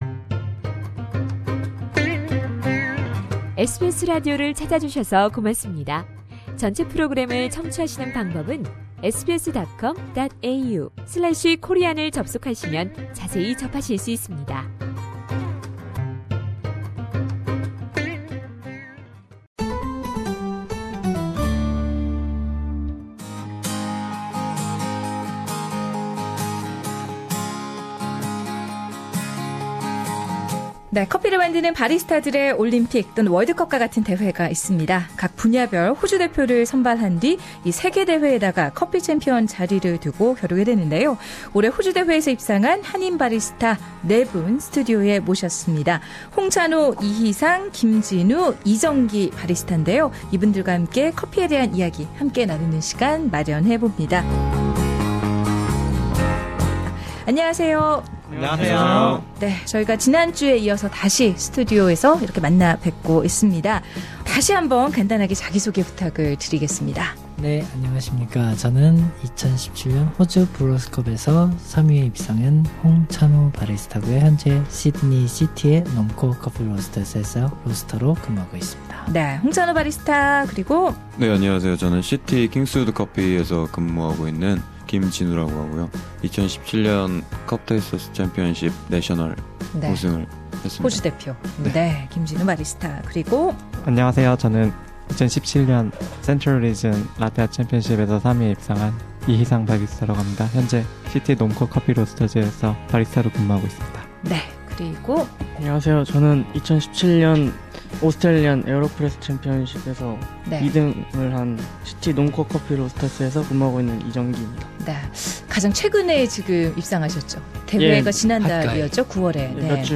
Four award-winning Korean baristas joined SBS studio to share their outstanding achievement and distinguished passion for coffee. In this last episode, the baristas give advice to coffee lovers about how to make a nice cup of coffee at home. Full interview is available on Podcast above.